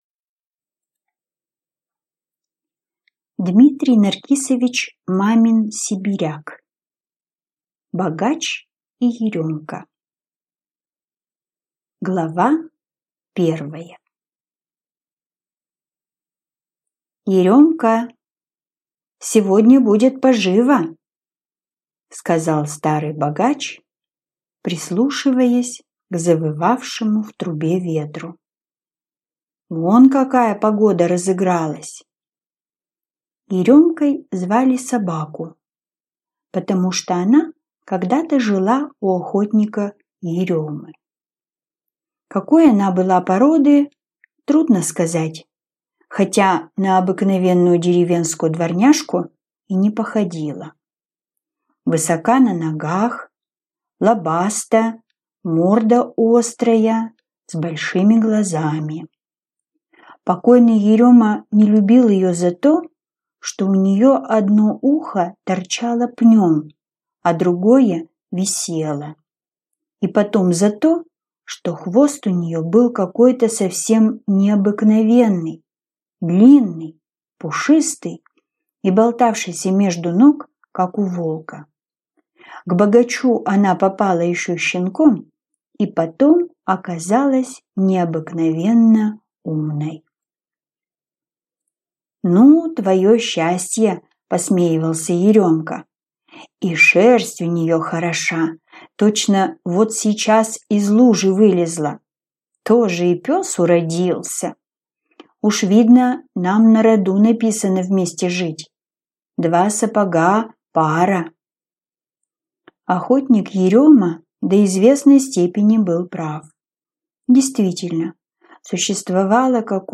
Аудиокнига Богач и Еремка | Библиотека аудиокниг